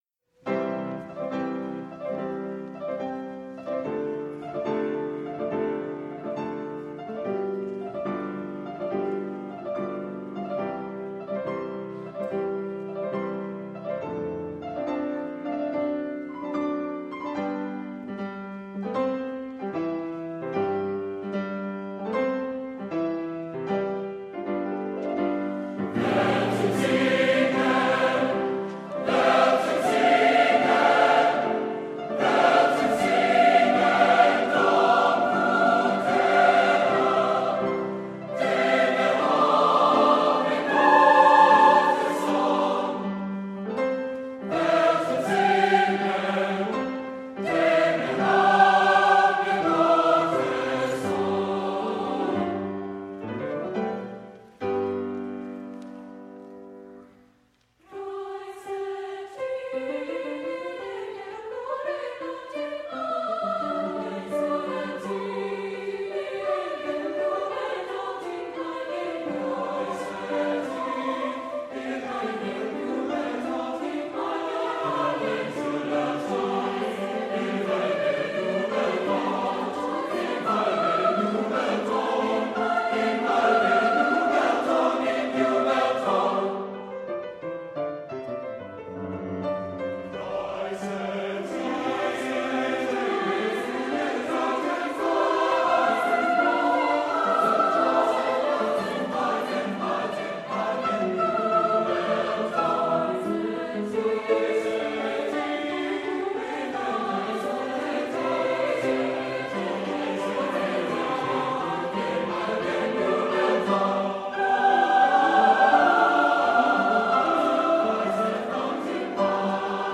Voicing: SATB Choir